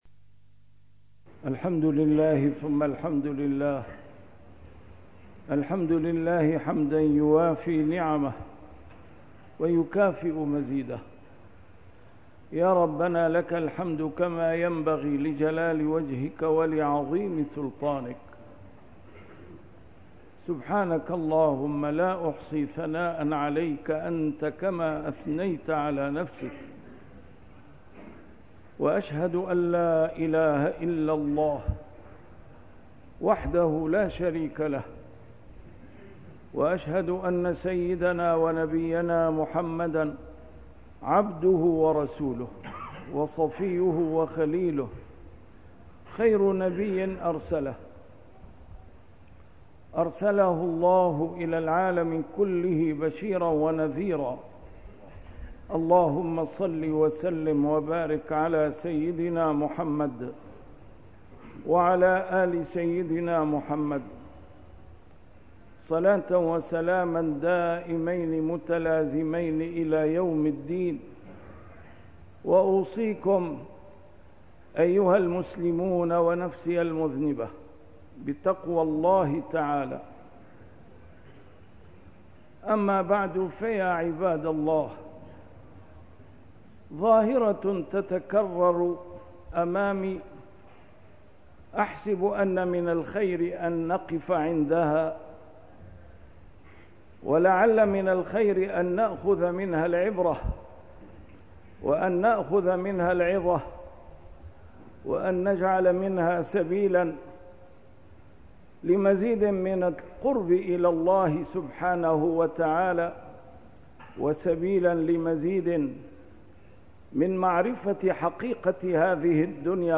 A MARTYR SCHOLAR: IMAM MUHAMMAD SAEED RAMADAN AL-BOUTI - الخطب - الكفران .. ظاهرة قديمة متجددة